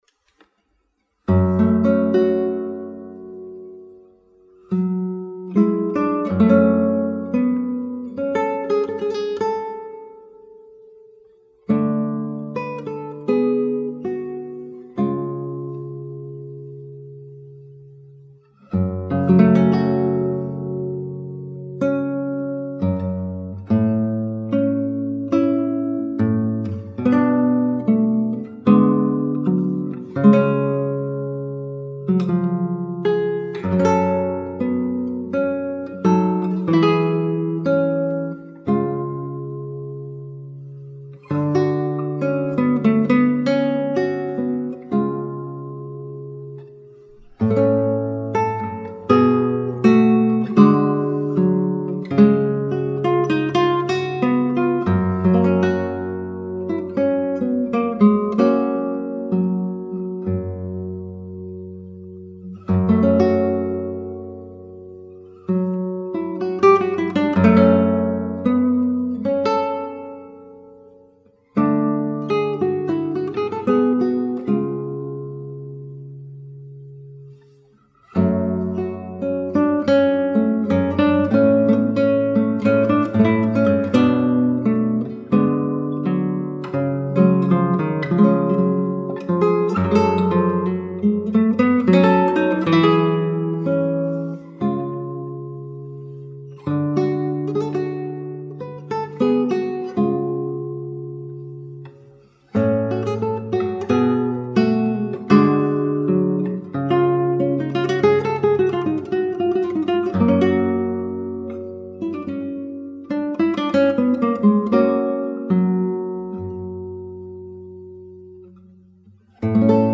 John Dowland - Lachrimae - Guitare Classique
L’articulation, phrasé, interprétation et son tout y est !